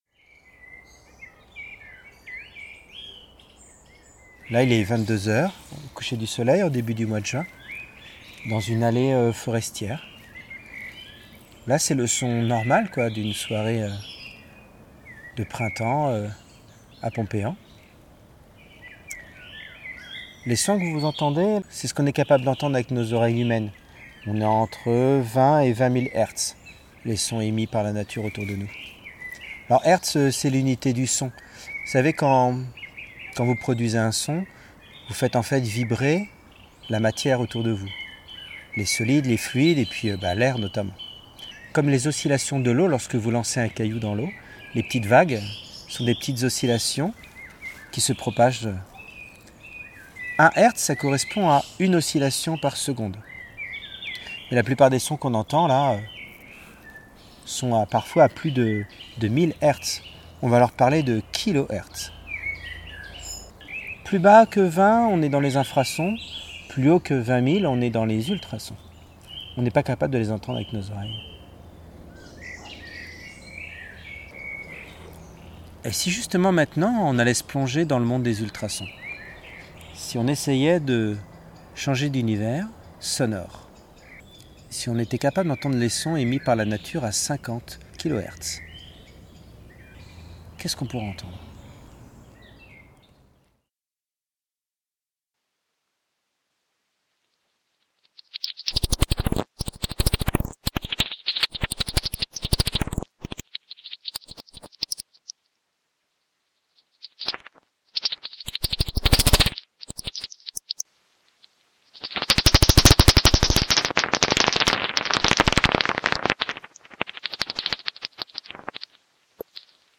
L’oreille humaine capte globalement les sons émis autour de nous entre les fréquences de 20 à 20 000 Hertz. Cette friandise vous propose de vous plonger dans un autre monde sonore, celui des ultrasons, en écoutant ce que notre environnement sonore pourrait nous donner à entendre, un soir de printemps, si nous étions capables d’entendre les sons émis à des fréquences proche de 50 000 hertz (50 Khertz).
• Matériel utilisé: Enregistreur : Zoom H2n / montage avec Audacity